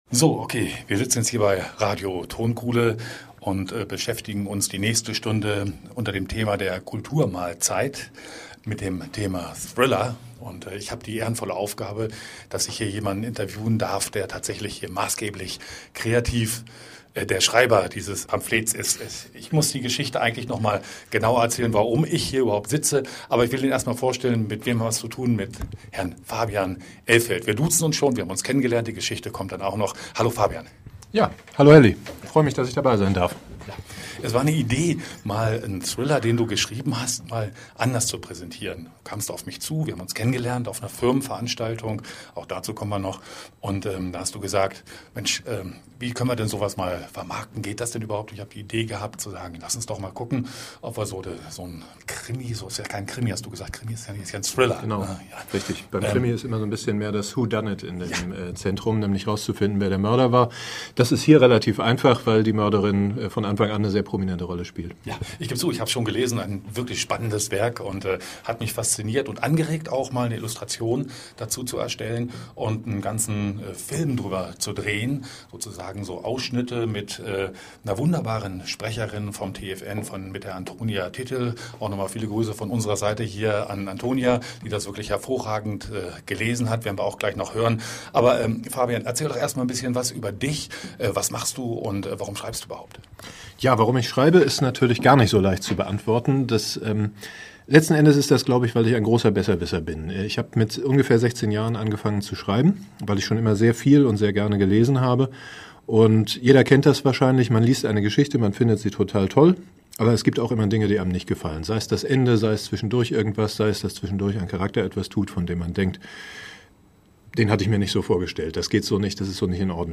(Falls euch ein paar Sachen komisch vorkommen: Ihr müsst euch das so vorstellen, dass wir völlig unvorbereitet einander gegenüber saßen, weil wir bis zum Beginn des Interviews davon ausgingen, dass jemand von Radio Tonkuhle dabei sein würde, der die Fragen stellt, außerdem nicht so genau wussten, was zwischendurch eingespielt wird, und dass hinterher noch einige Teile rausgeschnitten wurden, damit es in den Sendeplan passt.